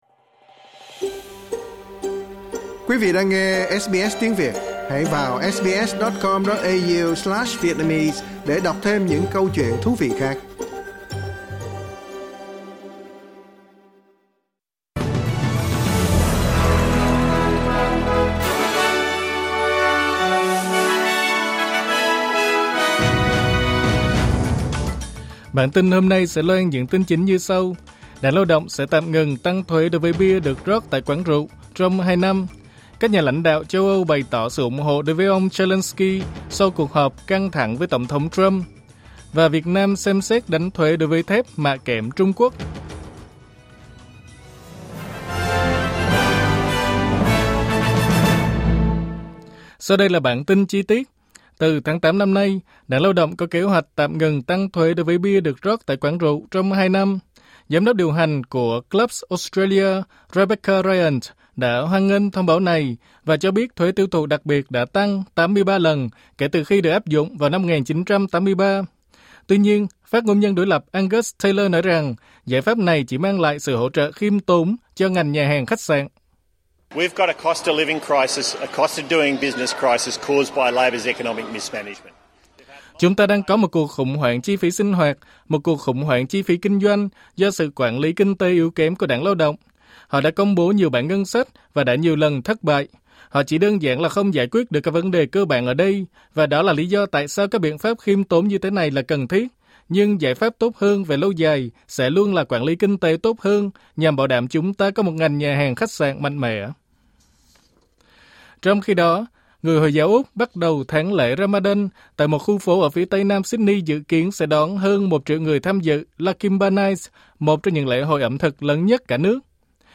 Vietnamese news bulletin Source: Getty